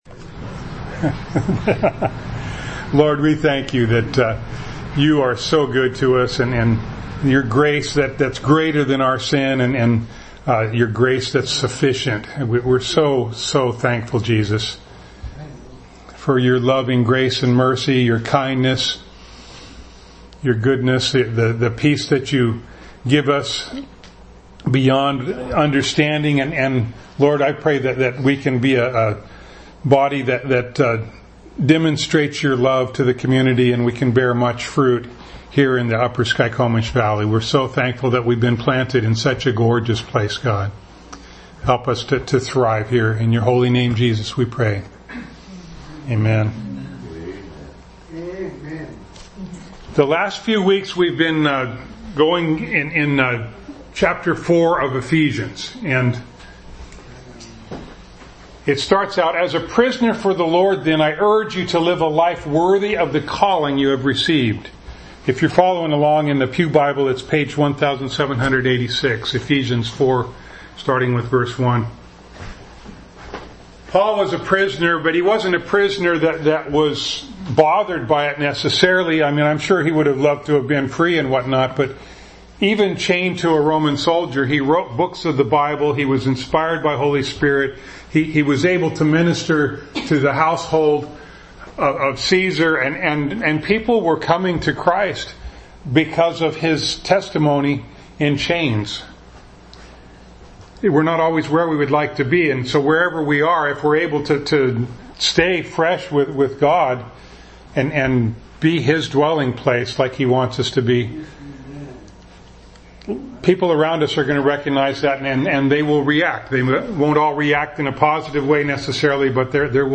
Ephesians 4:7 Service Type: Sunday Morning Bible Text